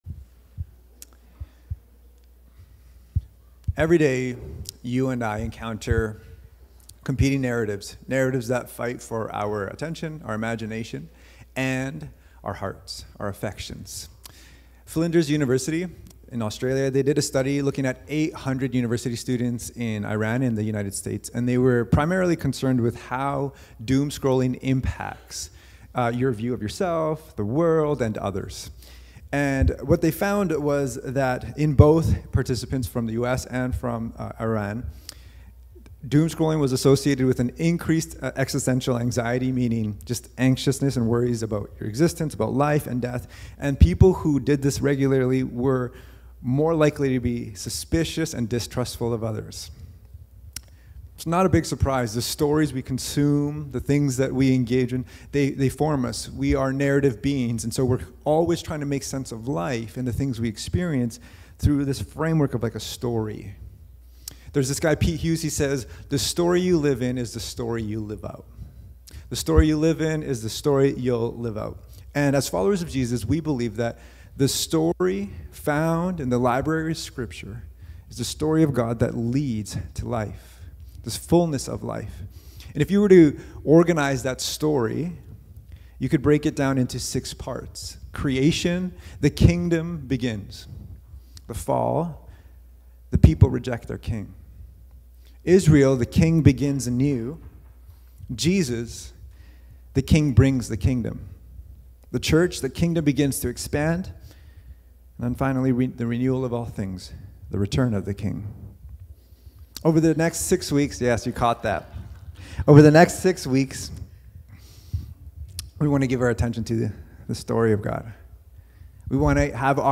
Cascades Church Sermons